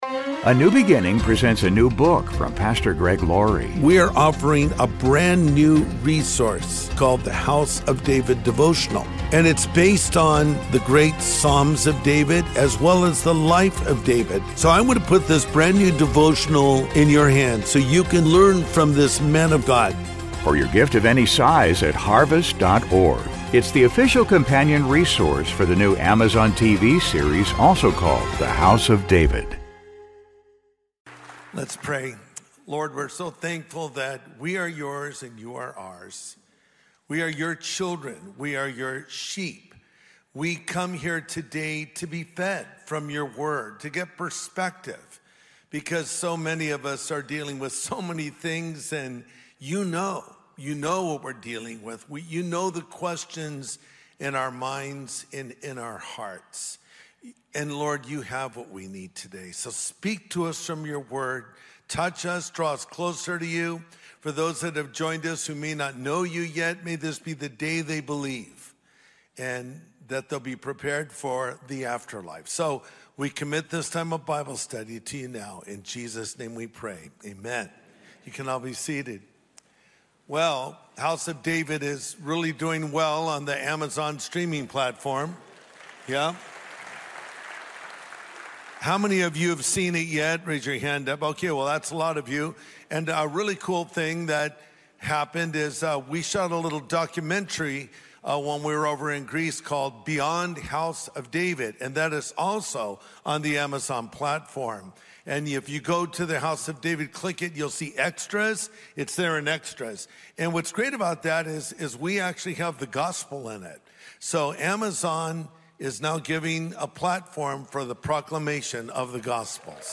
Jesus and the Heart That Grieves | Sunday Message Podcast with Greg Laurie